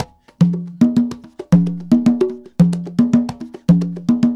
Funk Master Conga 01.wav